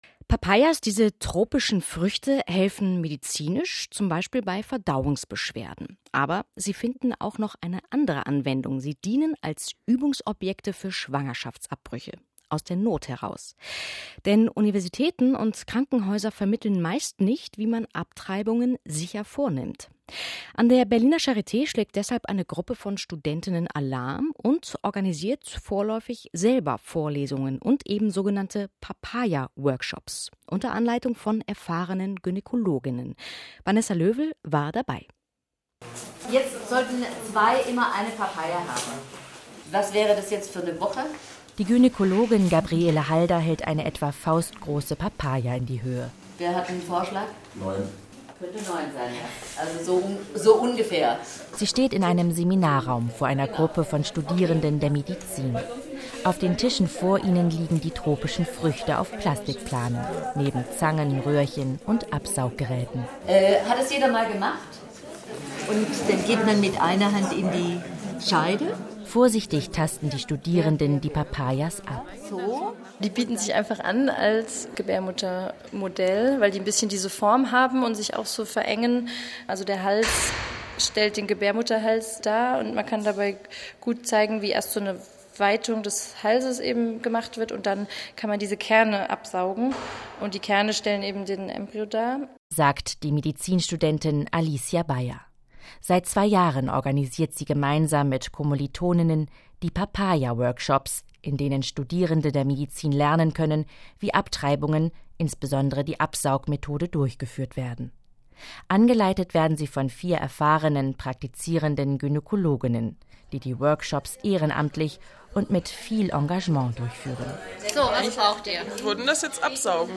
Das Kulturradio vom RBB hat einen Beitrag über unseren Papaya-Workshop gesendet.